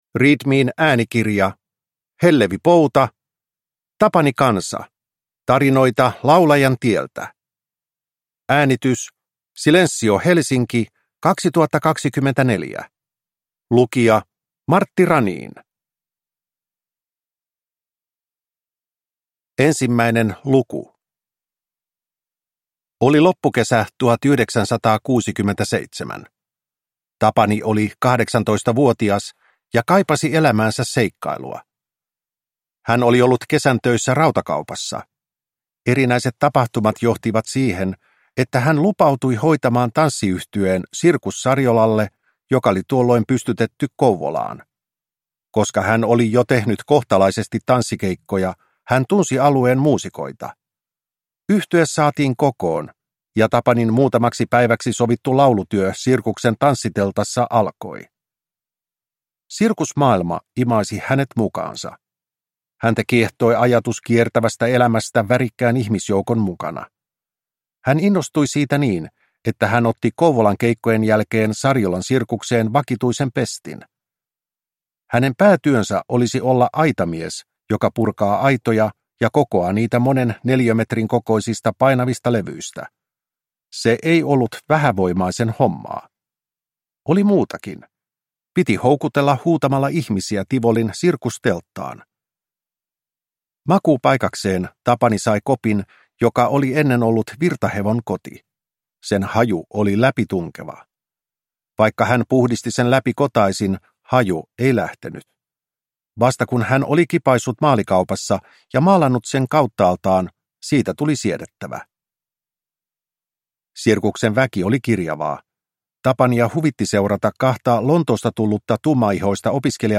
Tapani Kansa - Tarinoita laulajan tieltä – Ljudbok